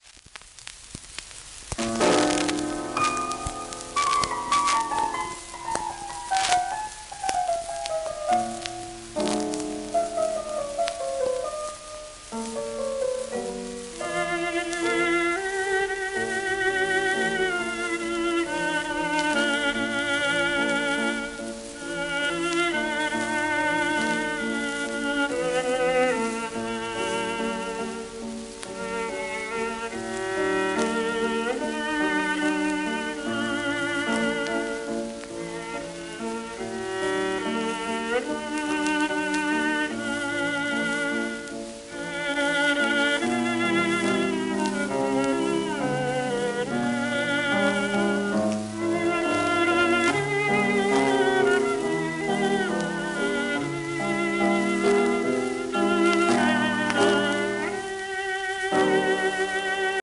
盤質B+ *導入溝荒れ,キズ,一部スレ